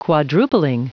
Prononciation du mot quadrupling en anglais (fichier audio)
Prononciation du mot : quadrupling